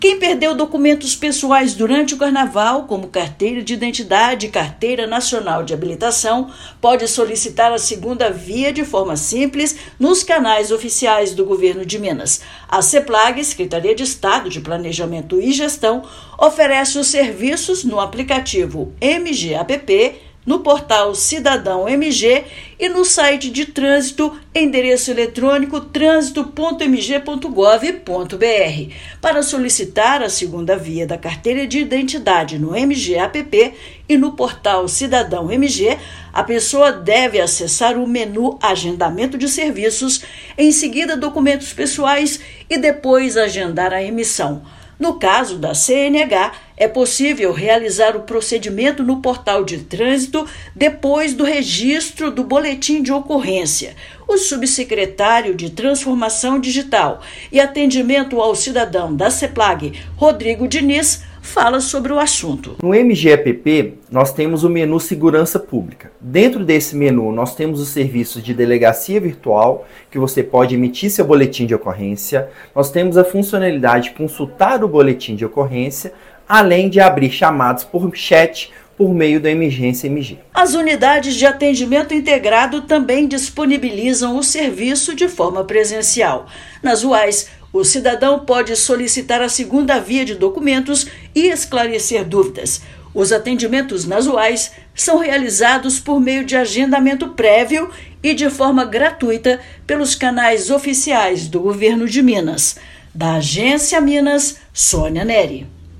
Cidadãos podem solicitar emissão de Carteira de Identidade e Carteira Nacional de Habilitação no MG App, no Portal Cidadão MG e em outras plataformas. Ouça matéria de rádio.